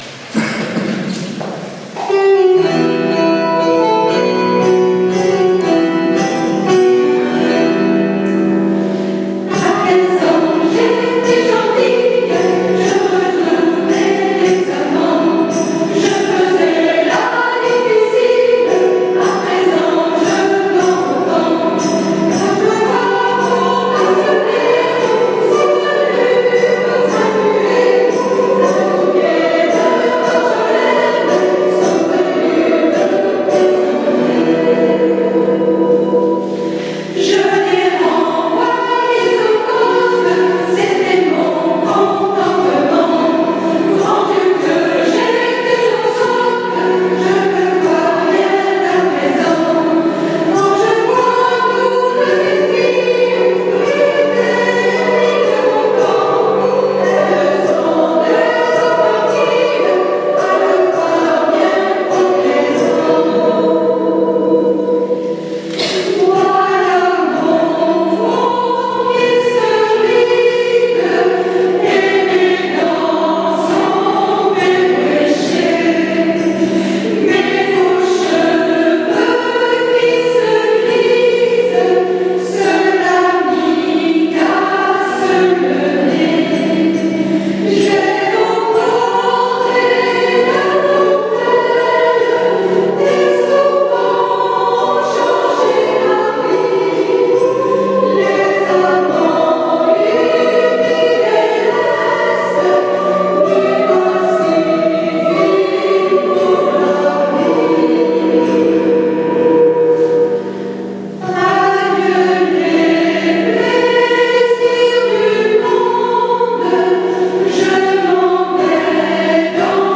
Mp3 15 ans et 2 amoureux Valsaintes 2012